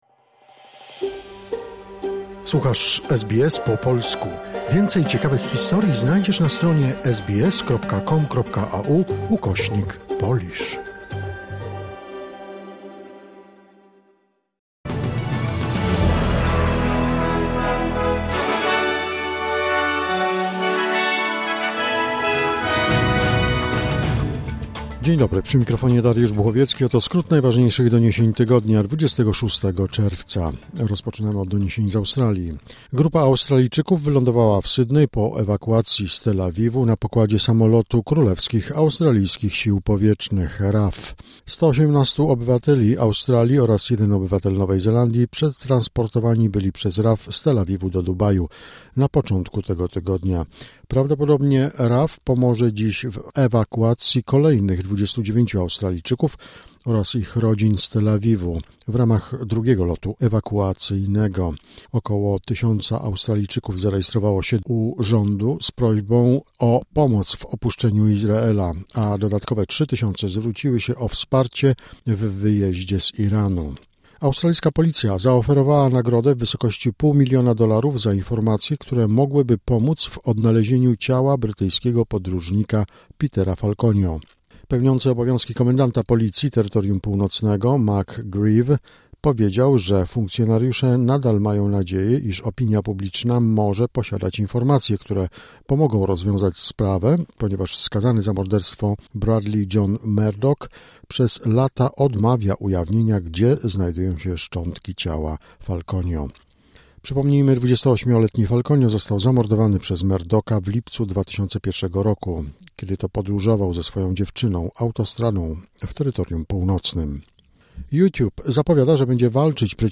Wiadomości 26 czerwca SBS Weekly Wrap